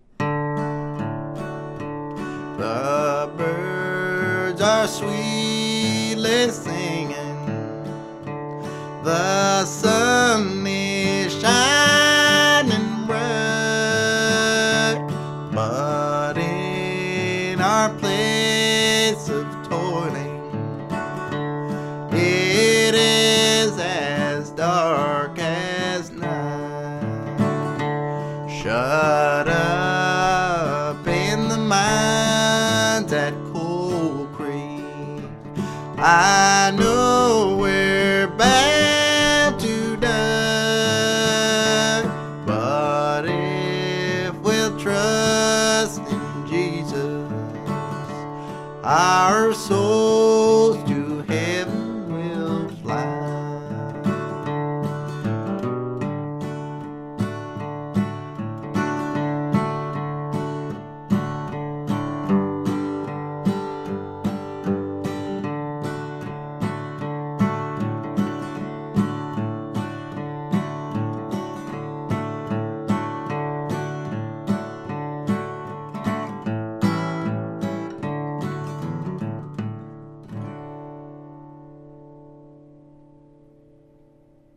key of D